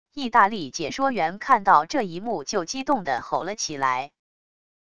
意大利解说员看到这一幕就激动的吼了起来wav音频